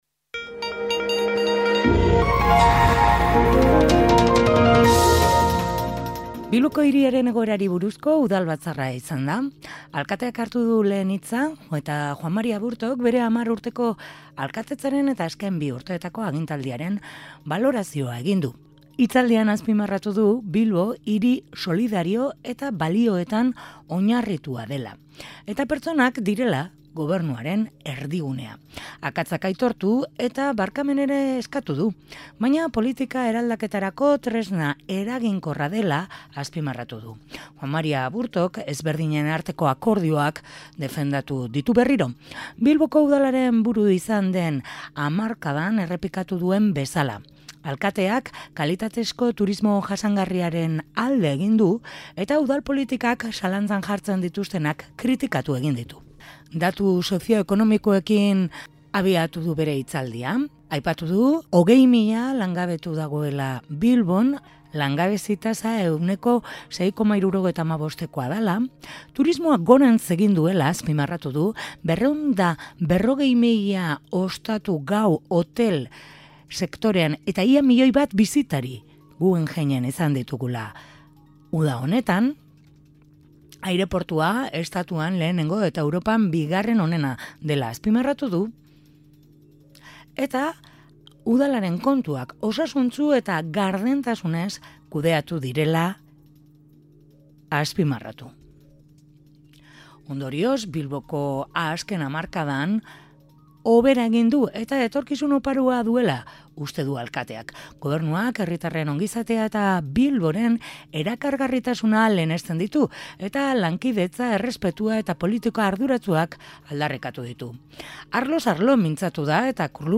Bilboko Hiriaren Egoerari buruzko Udalbatzarra izan da , Alkateak hartu du lehenengo hitza eta Juan Mari Aburto bere 10 urteko alkaldetzaren eta azken bi urteko agintaldiaren balantzea egin du. Hitzaldian azpimarratu du Bilbo hiri solidario eta balioetan oinarritua dela, eta pertsonak direla gobernuaren erdigunea. Akatsak aitortu eta barkamena eskatu du, baina politika eraldaketarako tresna eraginkorra dela azpimarratu du.